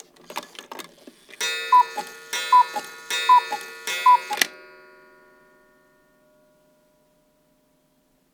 cuckoo_strike4.L.wav